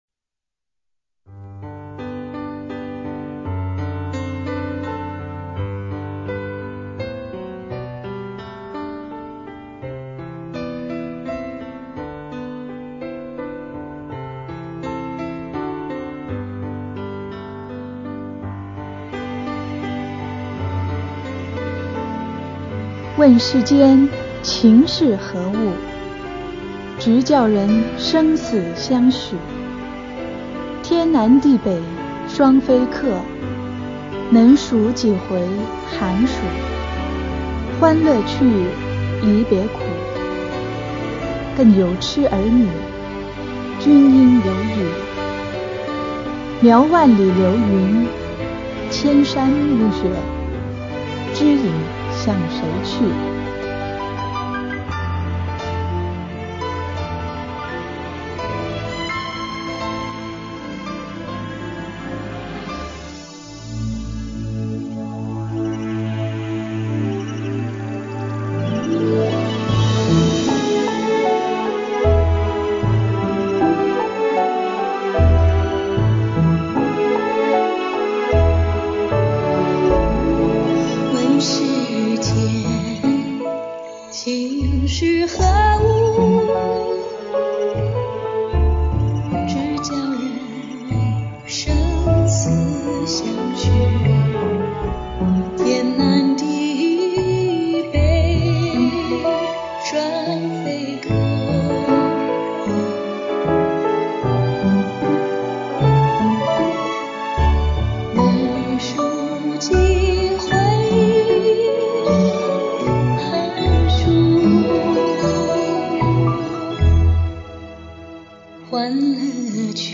朗诵/演唱